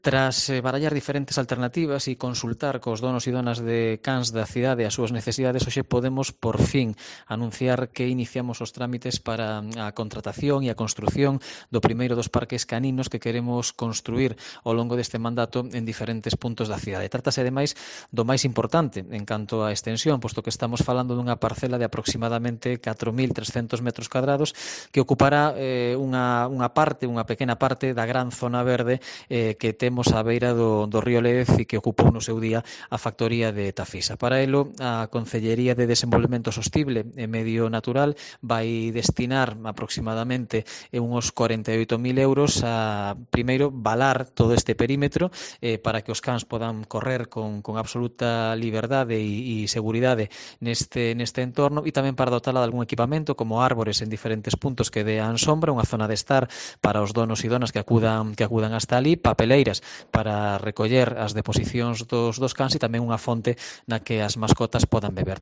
Declaraciones de Iván Puentes, concejal de Medio Natural de Pontevedra